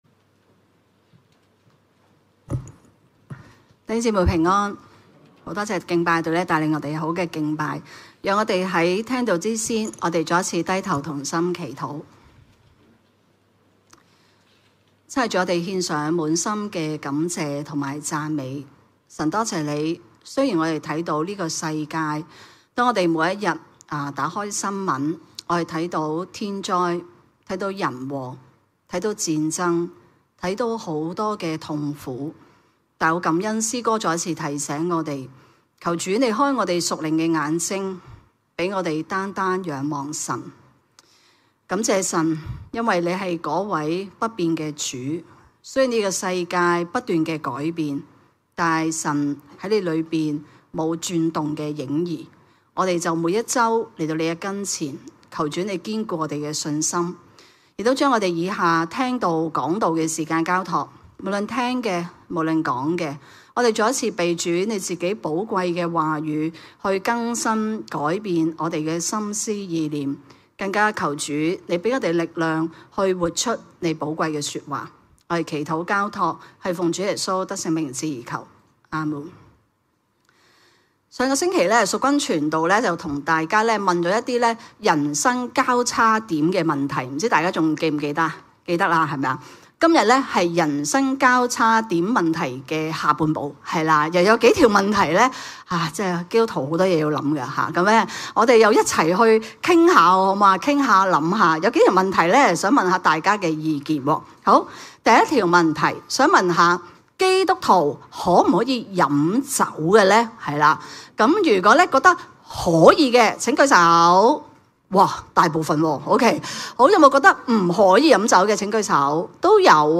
證道重溫